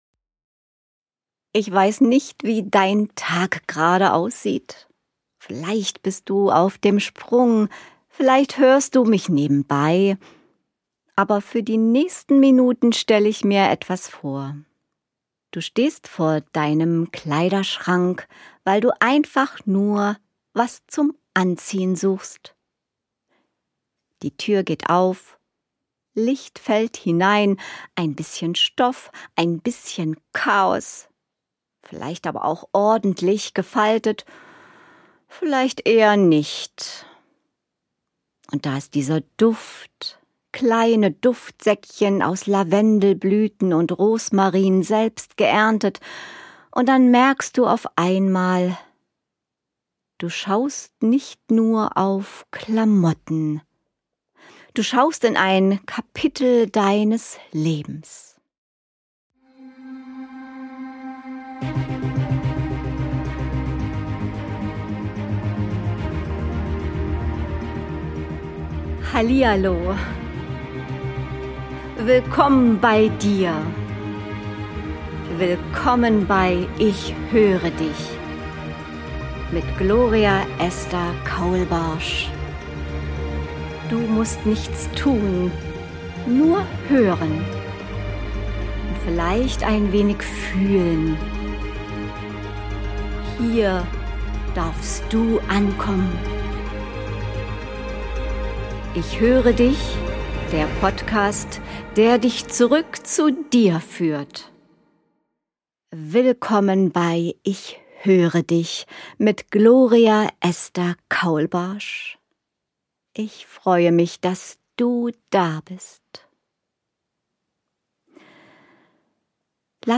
Aufgenommen im Greve Studio Berlin.